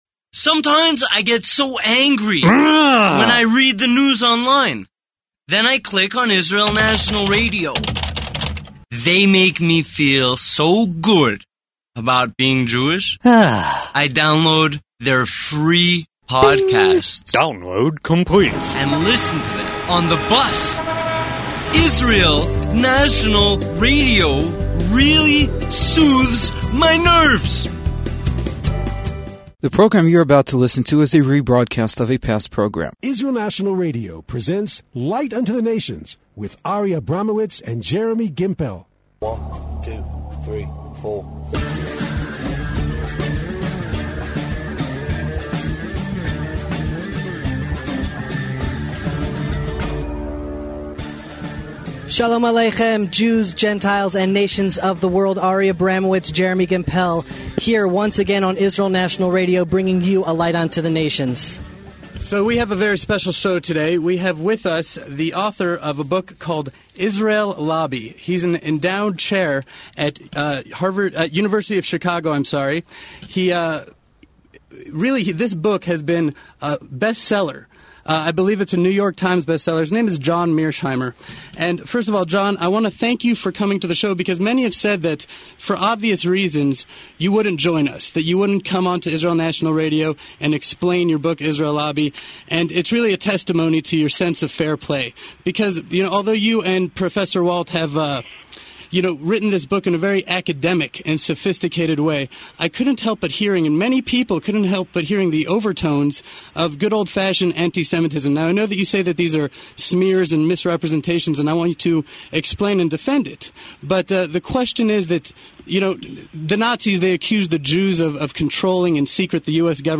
John Mearsheimer, during his 2008 visit to Israel, interviewed on Arutz Sheva‘s Israel National Radio show.